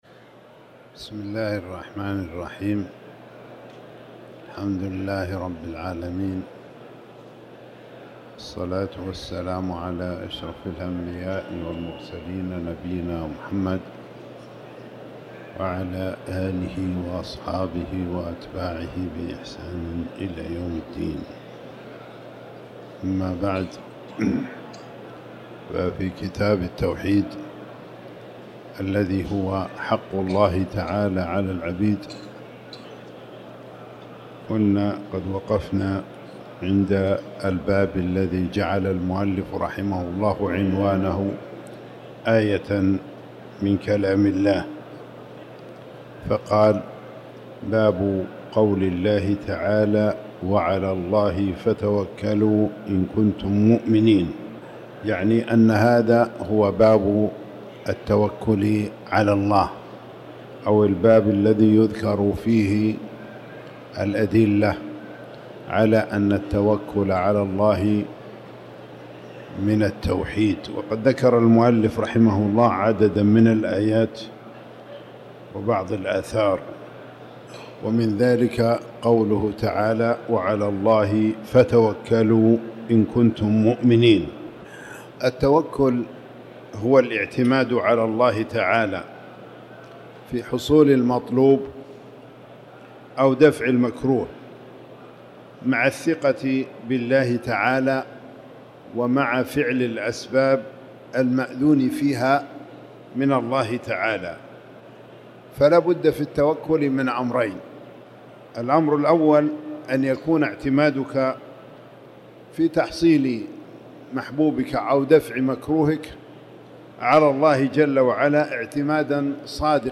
تاريخ النشر ٢٤ رجب ١٤٤٠ هـ المكان: المسجد الحرام الشيخ